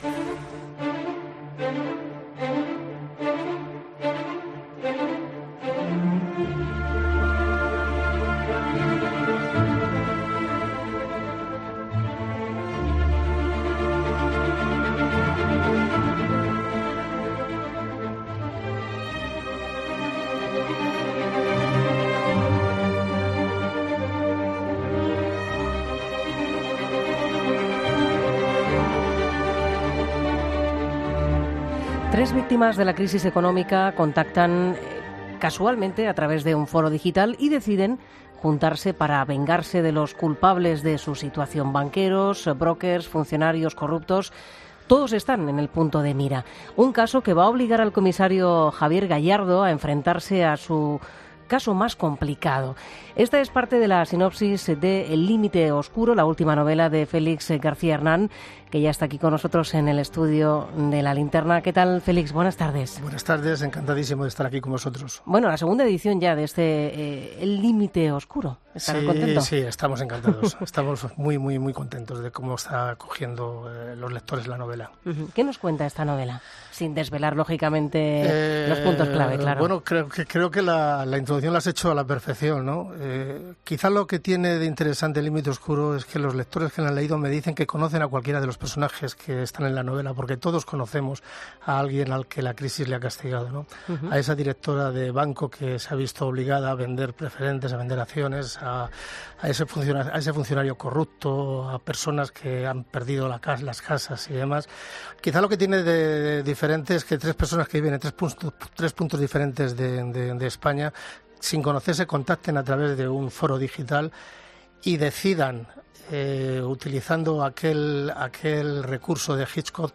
La luz de La Linterna Entrevista